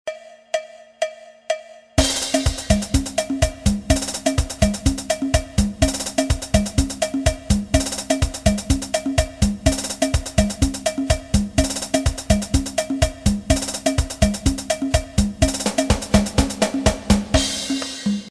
Section rythmique du camdomblé ( ijexa )
Pour la partie drums, la note (la) est jouée par une cloche et le rythme s'apparente à une figure de claves cubaines 3/2.La grosse caisse, le (la grave) joue une figure similaire au xote et enfin les charleys font un remplissage.
Figure Section rythmique candomblé Téléchargez ou écoutez dans le player.